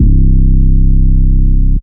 DDK1 808 3.wav